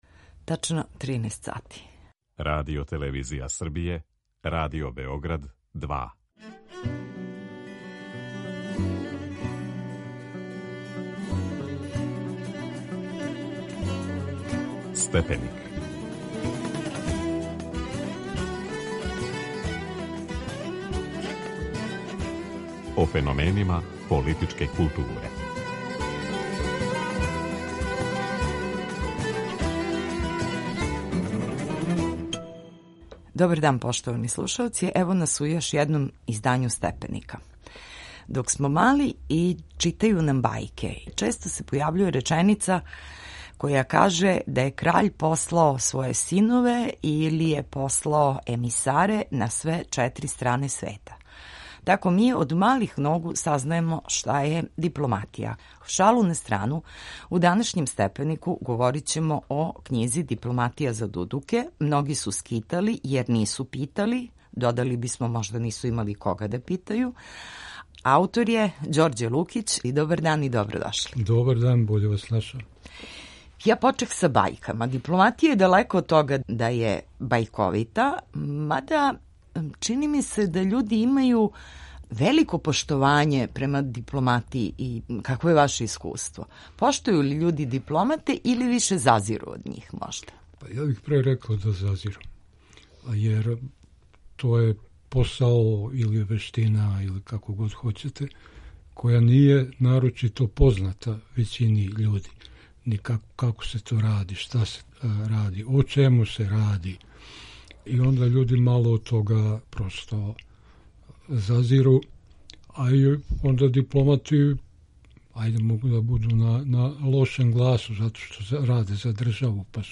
Гост